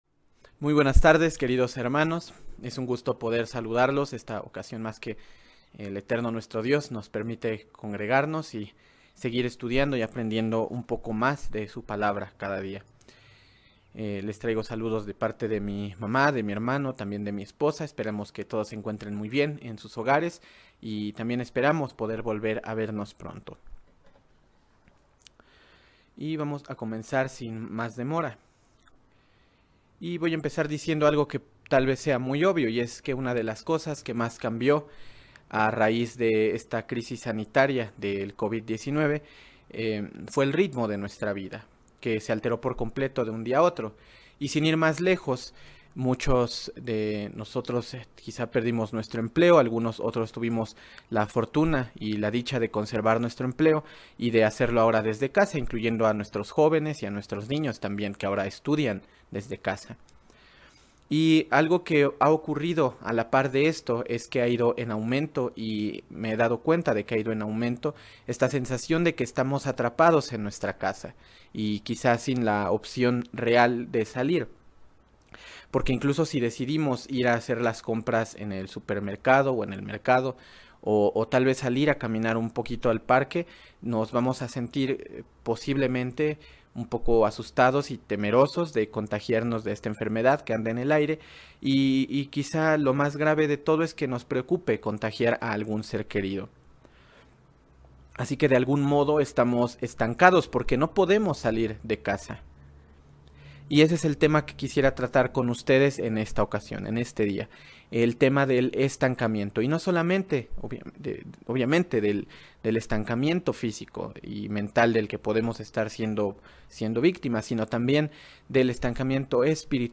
El estancamiento espiritual es un peligro constante en la vida del cristiano. Una forma de superarlo, es profundizar en nuestro entendimiento del Plan de Salvación de Dios y lo que él desea de nosotros. Mensaje entregado el 20 de febrero de 2021.